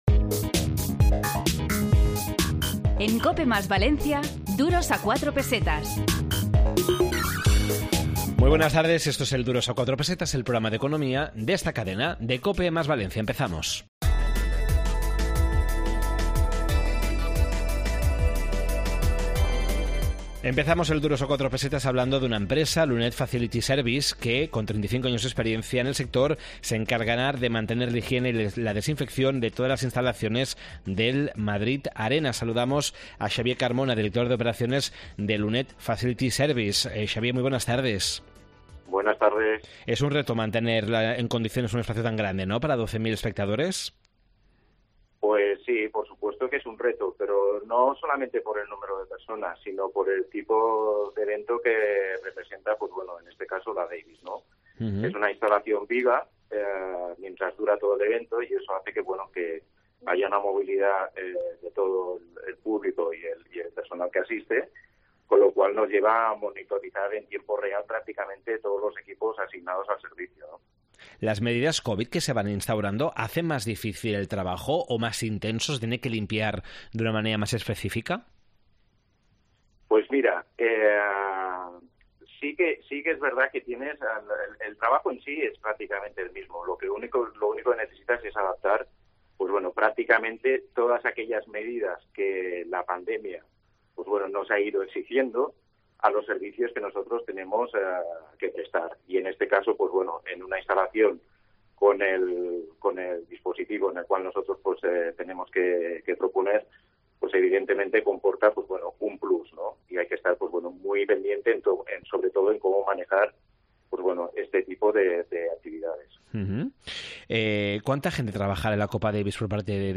Esta semana en Duros a 4 Pesetas de COPE + Valencia, en el 92.0 de la FM, hemos preparado un programa dedicado a limpieza y mantenimiento de empresas, la reparación de plásticos, y el emprendimiento.